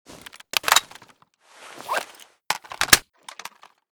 sig550_reload.ogg